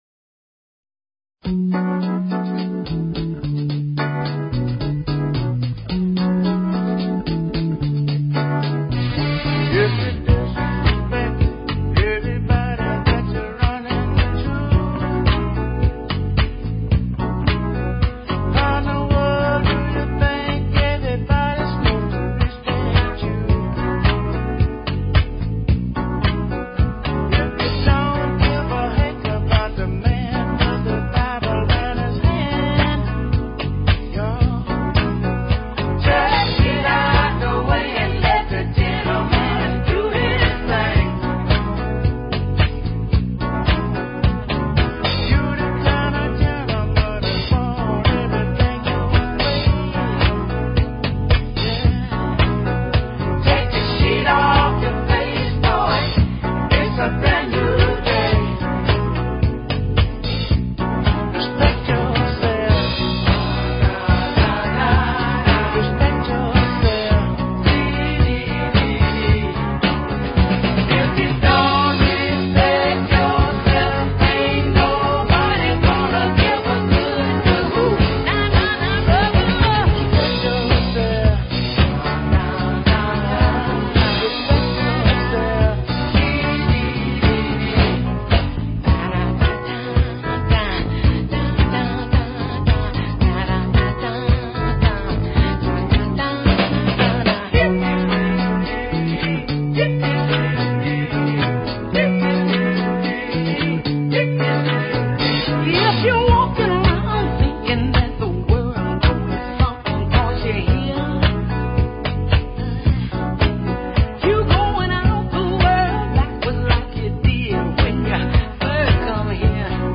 Talk Show Episode
Live On Air Readings